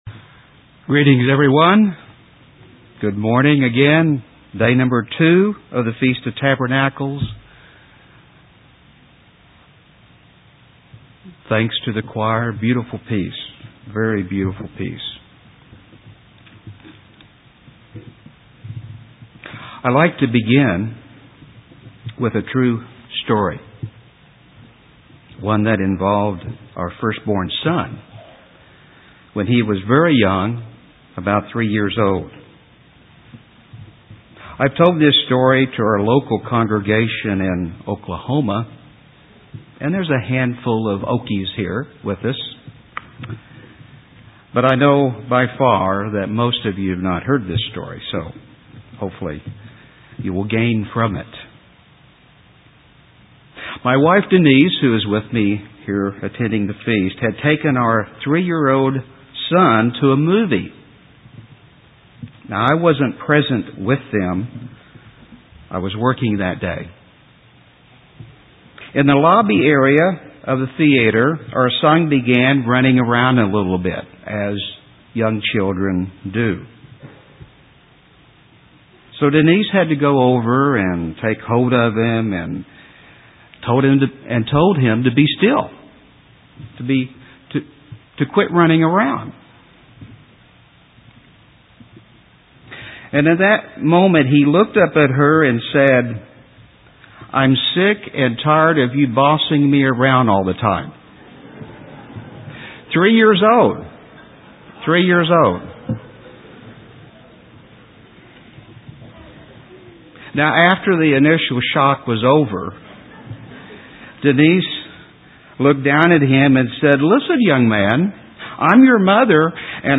Second Sermon on Day 2 FOT New Braunfels.